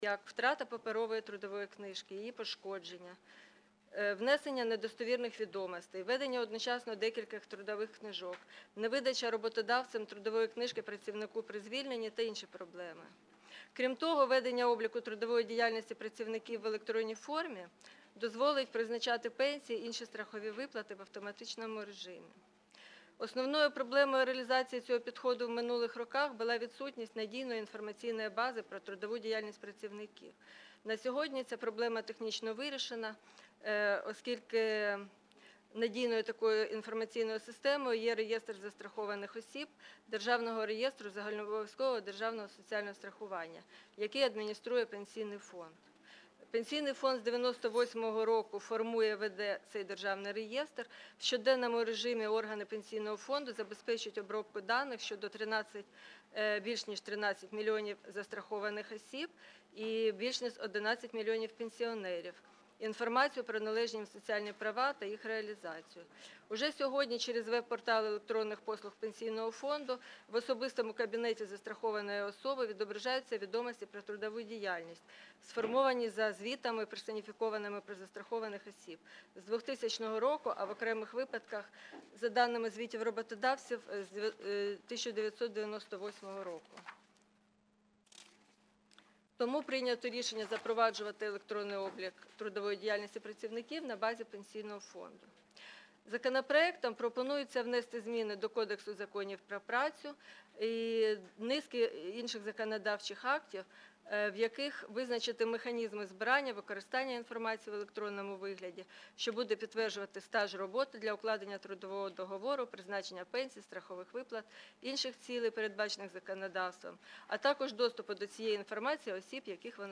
Аудіозапис засідання Комітету від 19.02.2020